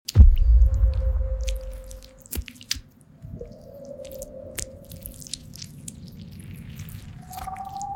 Dirty, sweaty hands slicing tiny sound effects free download
sweaty hands slicing tiny Mp3 Sound Effect Dirty, sweaty hands slicing tiny wiggling gelatinous creatures. Wet, squishy ASMR sounds create a creepy but oddly satisfying experience.